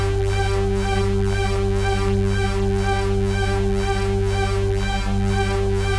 Index of /musicradar/dystopian-drone-samples/Tempo Loops/120bpm
DD_TempoDroneD_120-G.wav